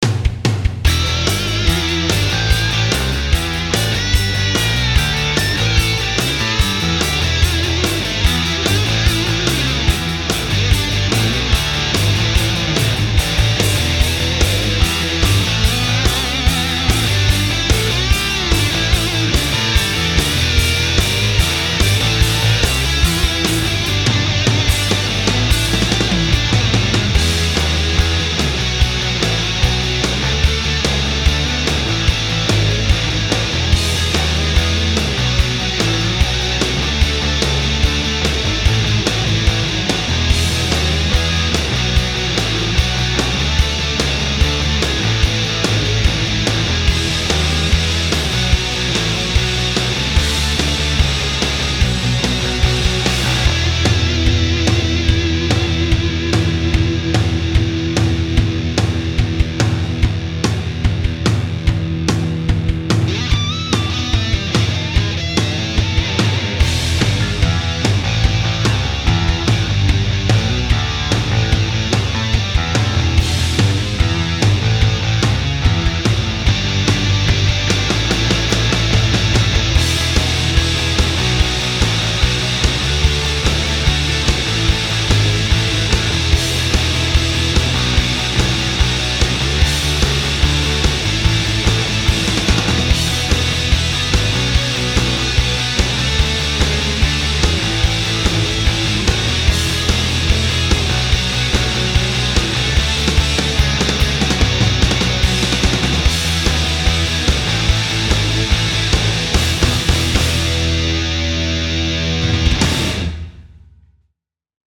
modern music for television, advertising, radio and film